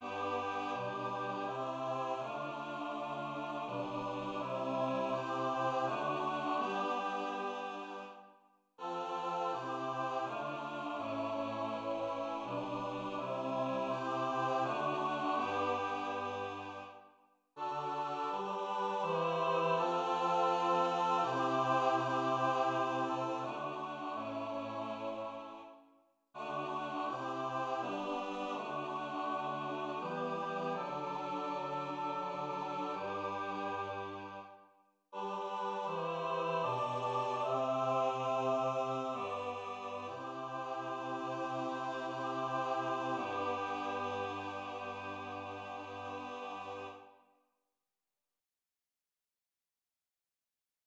Title: Jam sol recedit Composer: Melchior Franck Lyricist: Number of voices: 4vv Voicing: SATB Genre: Sacred, Office hymn
Language: Latin Instruments: A cappella